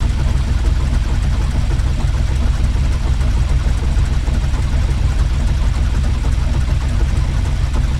train-engine.ogg